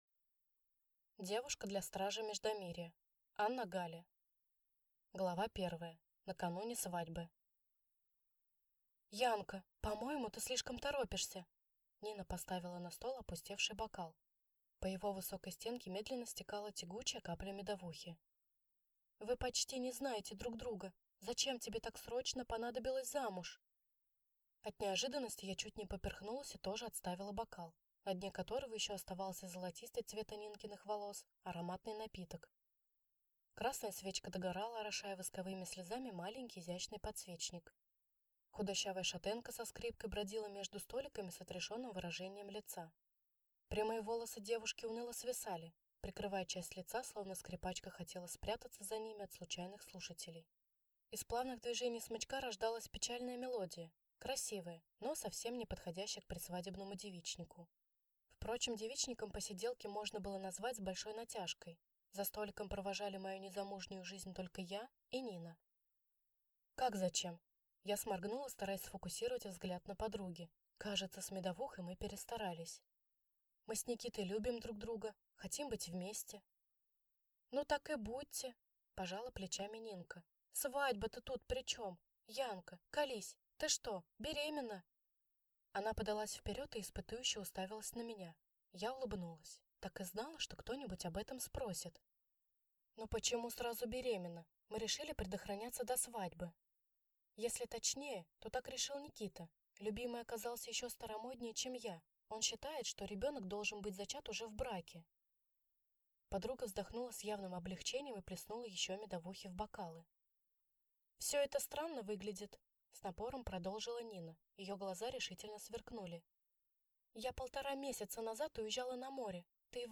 Аудиокнига Девушка для стража Междомирья | Библиотека аудиокниг